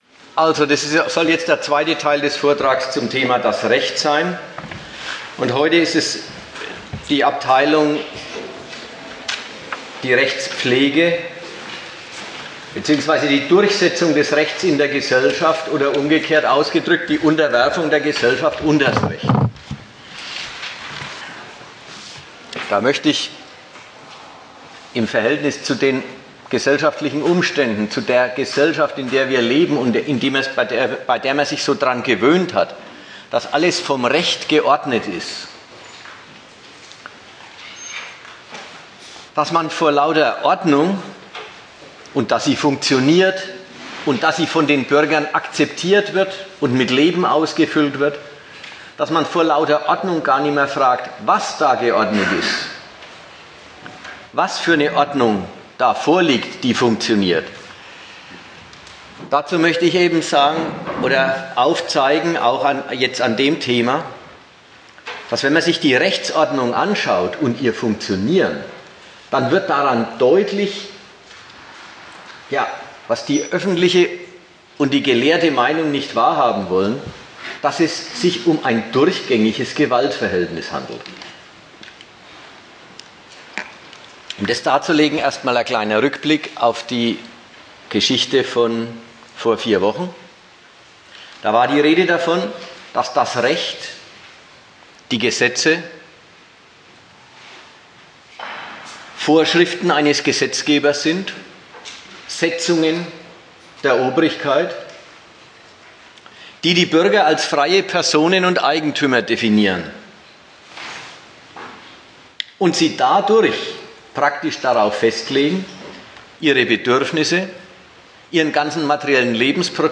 Gastreferenten der Zeitschrift GegenStandpunkt